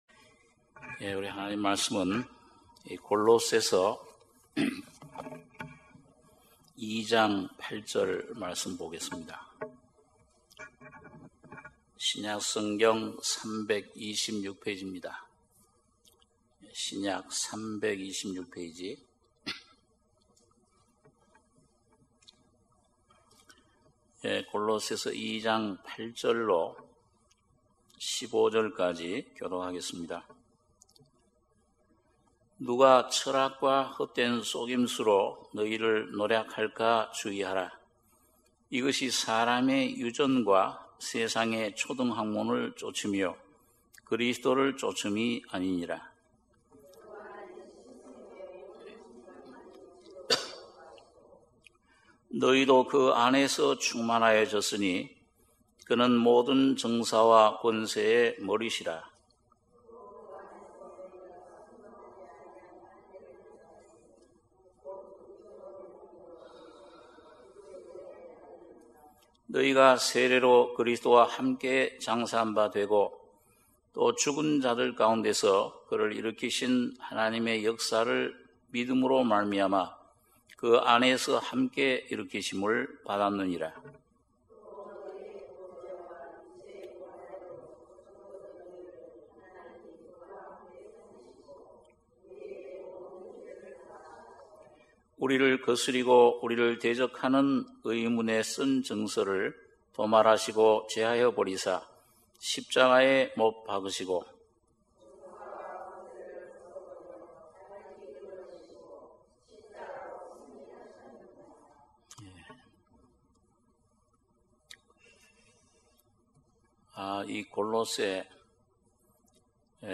수요예배 - 골로새서 2장 8절~15절